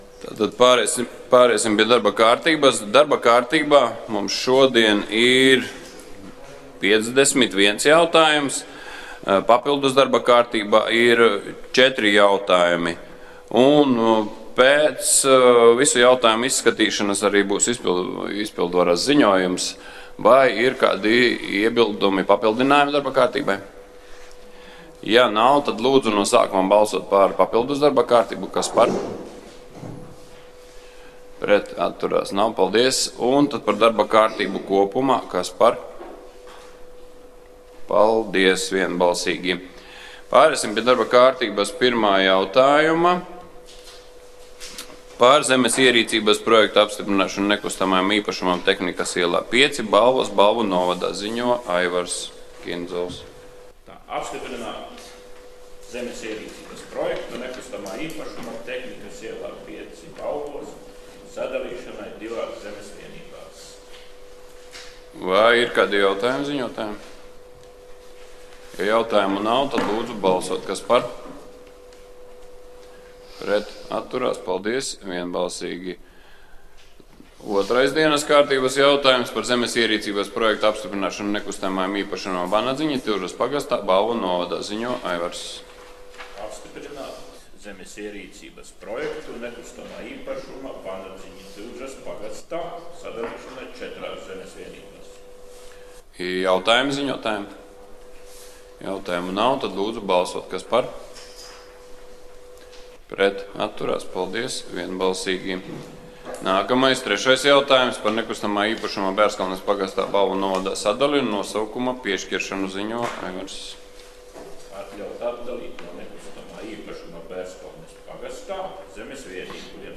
23. jūlija domes sēde